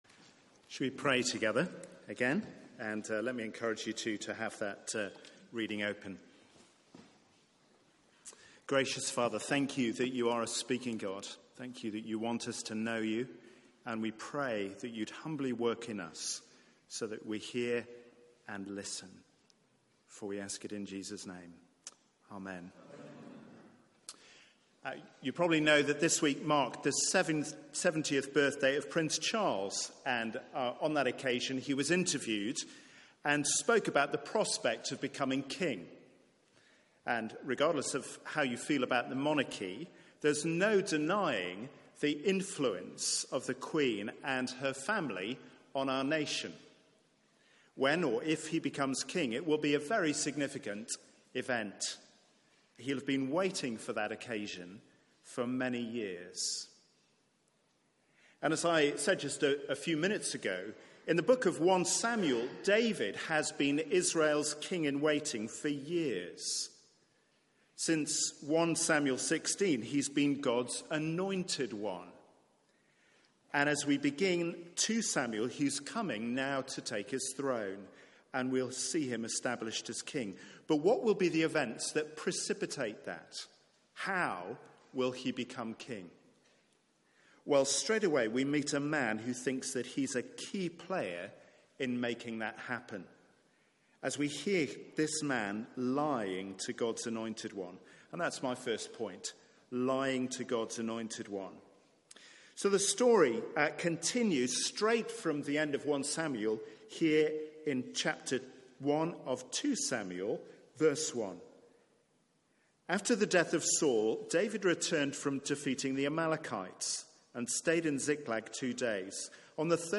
Media for 6:30pm Service on Sun 18th Nov 2018
Sermon